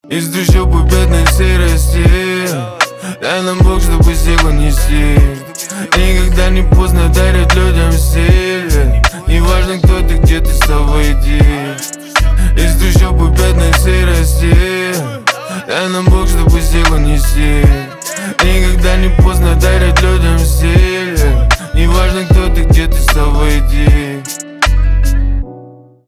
русский рэп
гитара , пианино
грустные